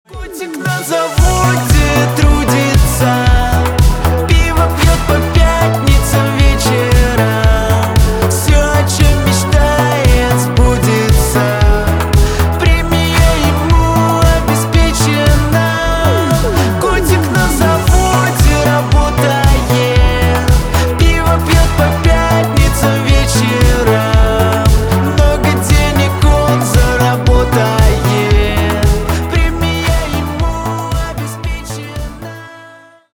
на русском веселые